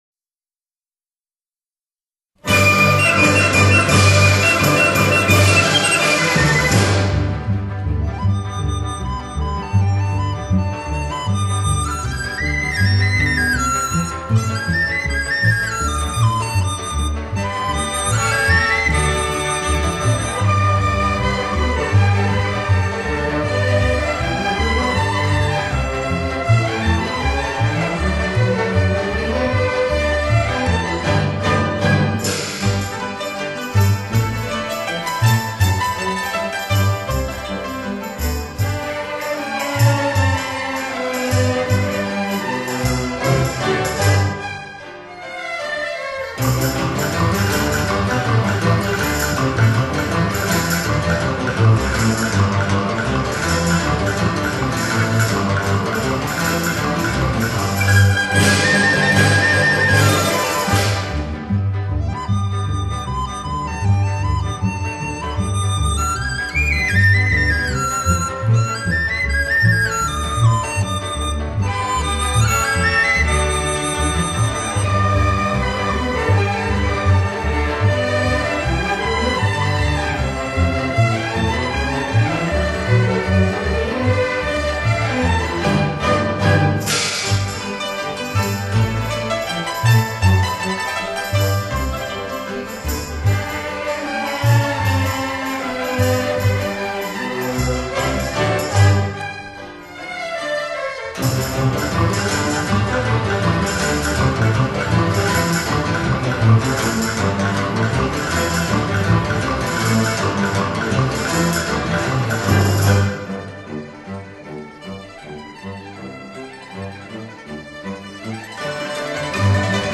丝竹乐合奏